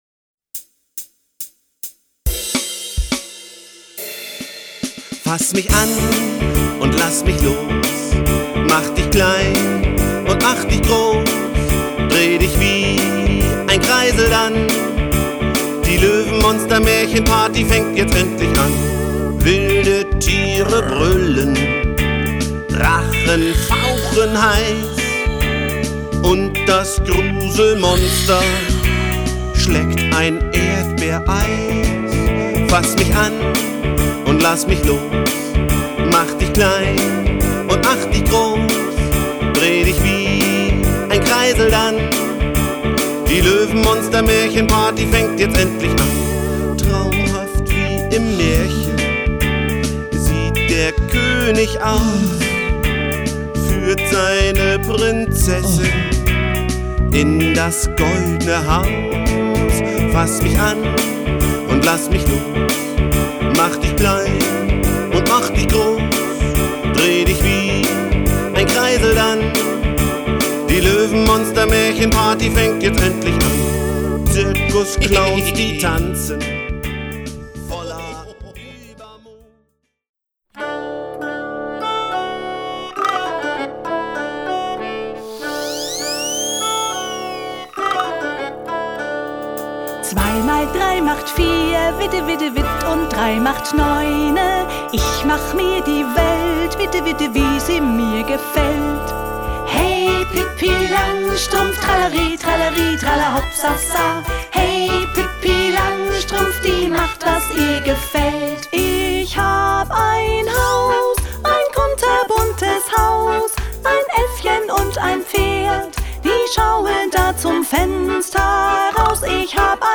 Themenwelt Kinder- / Jugendbuch Gedichte / Lieder